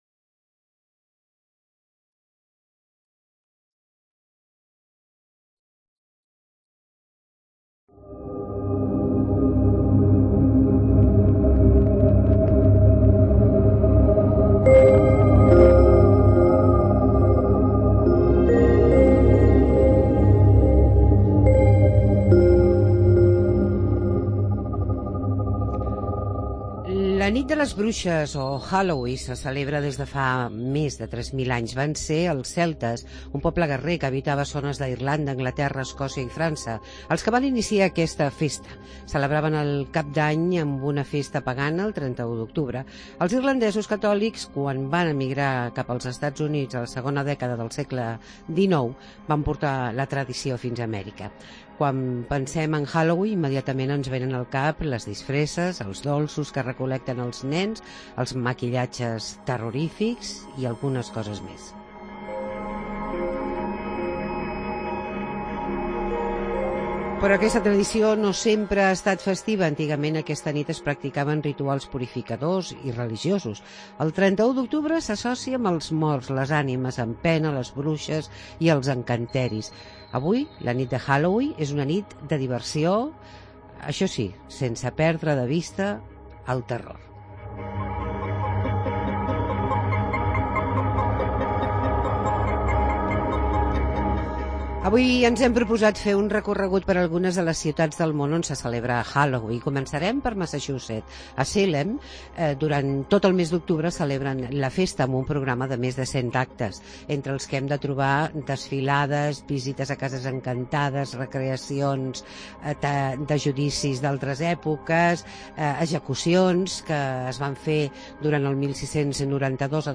Reportaje Halloween